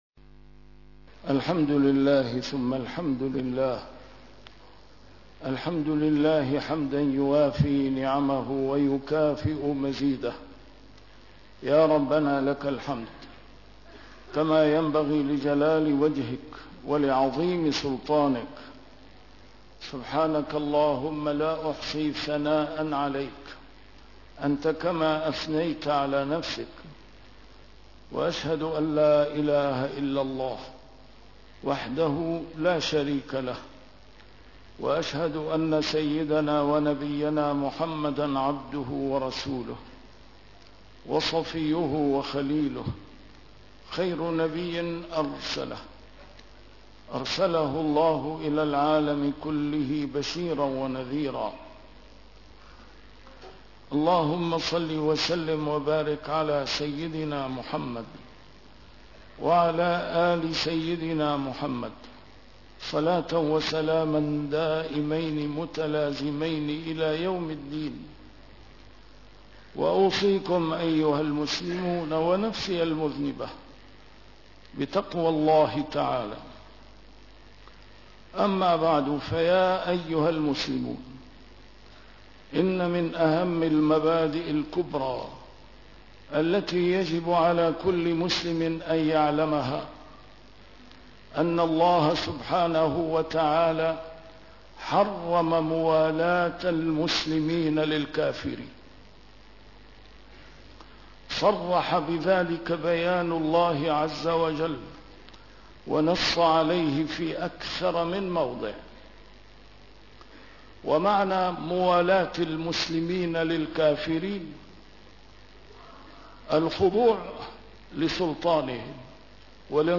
A MARTYR SCHOLAR: IMAM MUHAMMAD SAEED RAMADAN AL-BOUTI - الخطب - ألا هل بلغت اللهم فاشهد